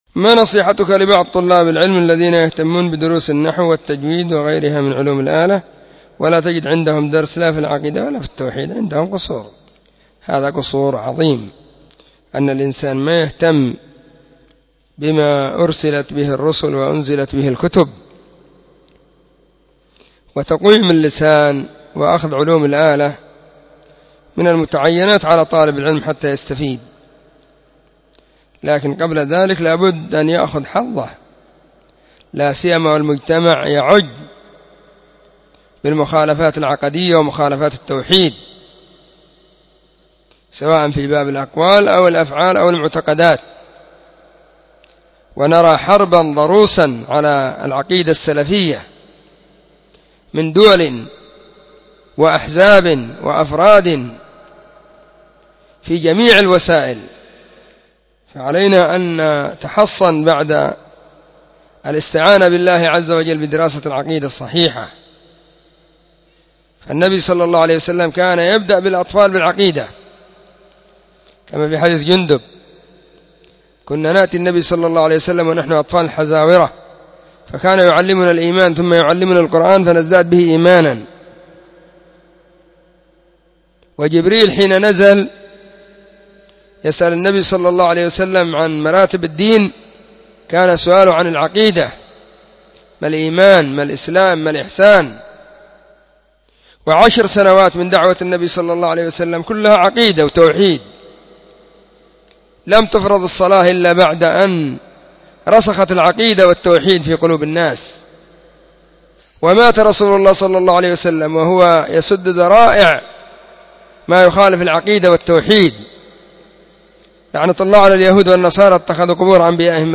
🔸🔹 سلسلة الفتاوى الصوتية المفردة 🔸🔹